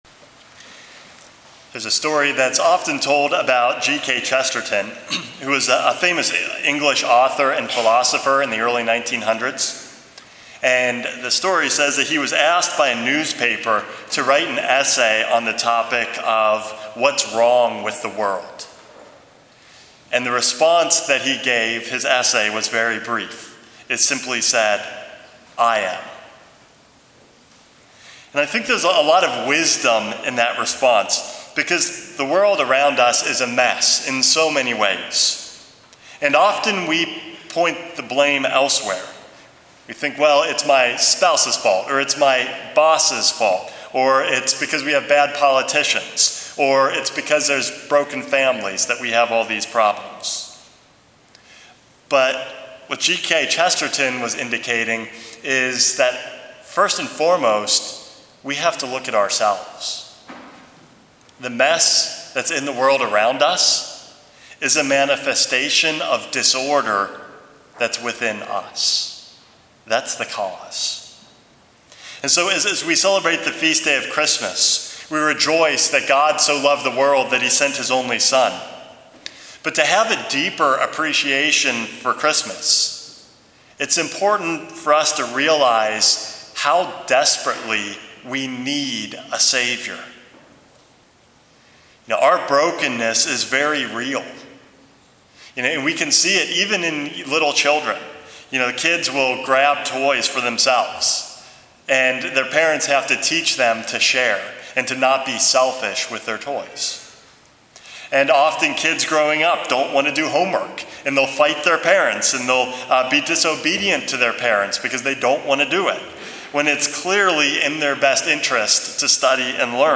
Homily #431 - We Need a Savior